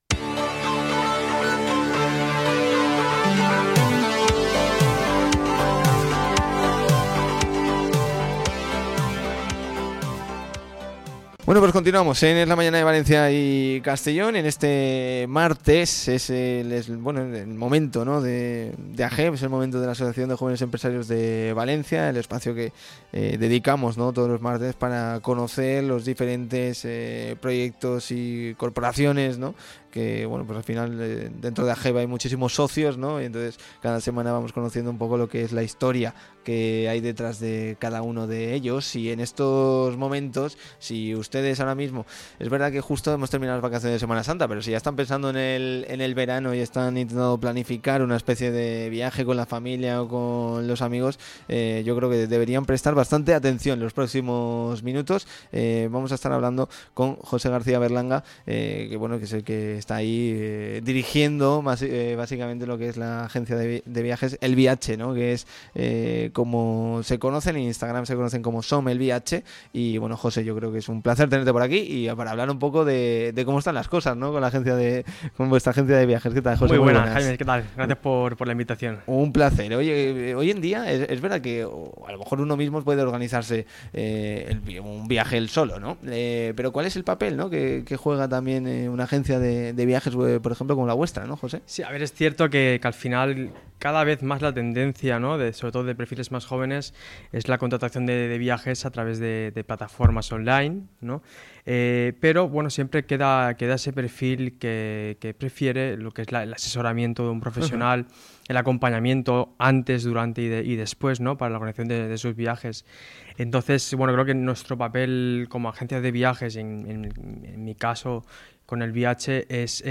Entrevista completa: